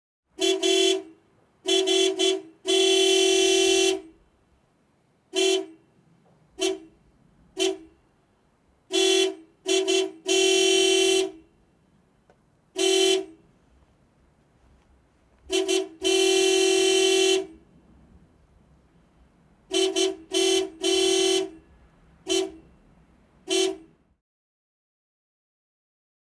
Звуки гудка автомобиля
Гудок машины - вот такой вариант (так сигналит легковушка)